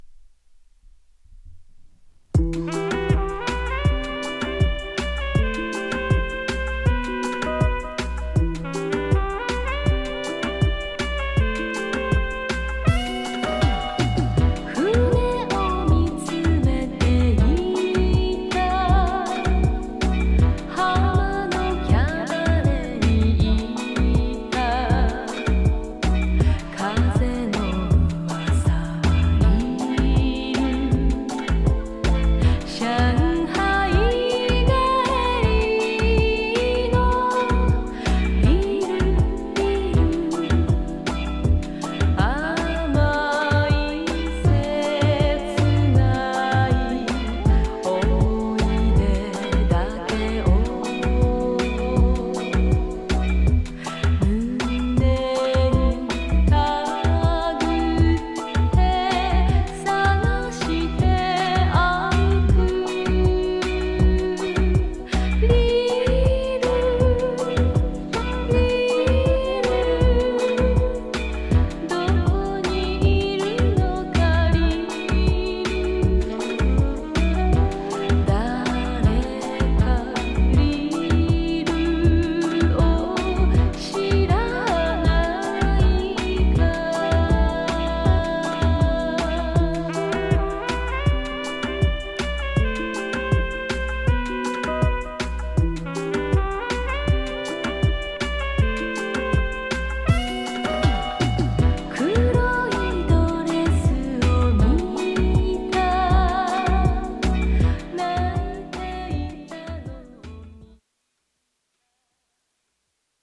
ジャンル(スタイル) JAPANESE POP / J-INDIES